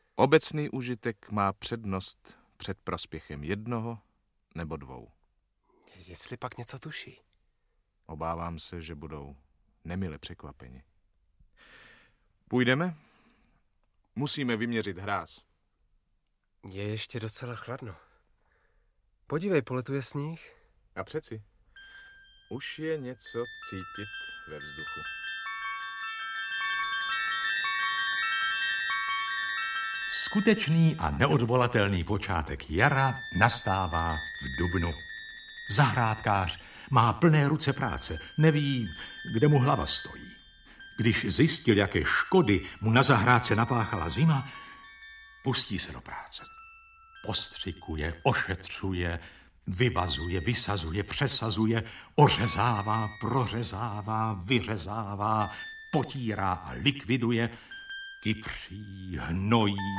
Audiobook
Read: Jan Kanyza